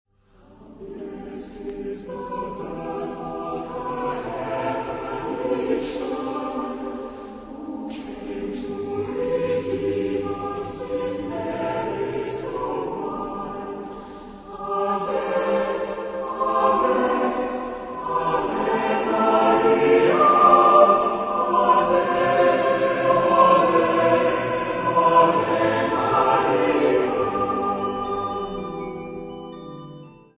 A Christmas carol
Género/Estilo/Forma: Canción de Navidad ; Sagrado
Tipo de formación coral: SATB  (4 voces Coro mixto )
Instrumentos: Organo (1)
Tonalidad : sol mayor